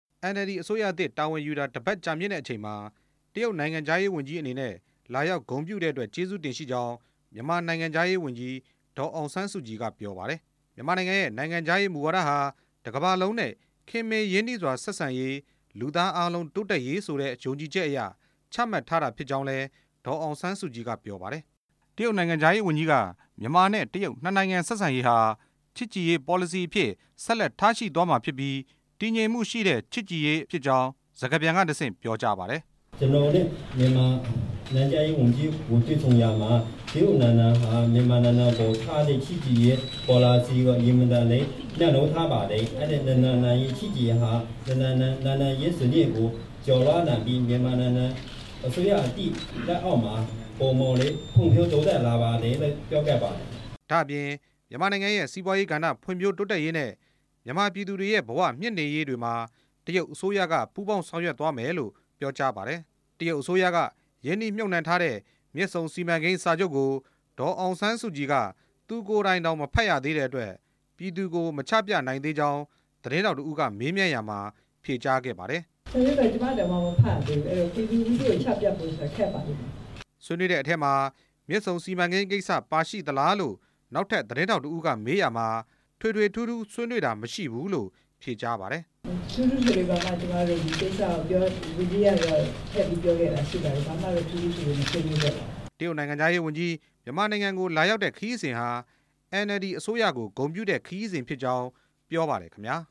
တရုတ်နိုင်ငံခြားရေးဝန်ကြီး နဲ့ ဒေါ်အောင်ဆန်းစုကြည် ပူးတွဲသတင်းစာရှင်းလင်း